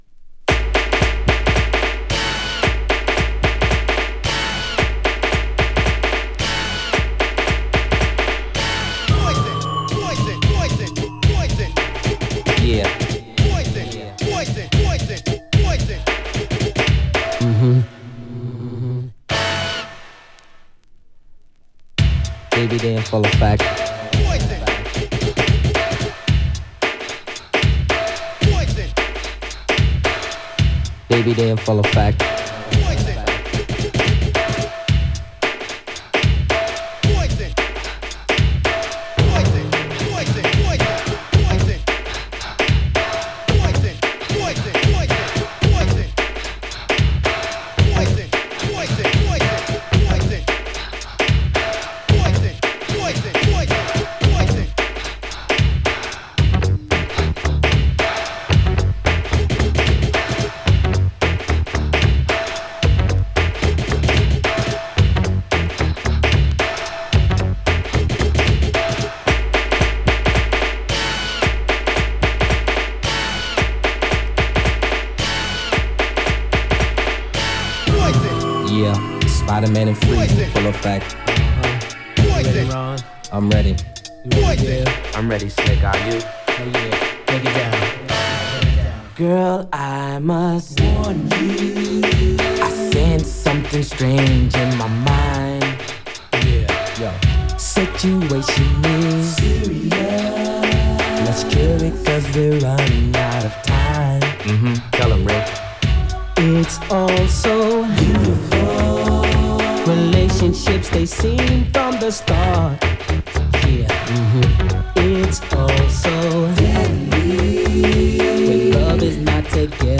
NEW JACK SWING CLASSIC!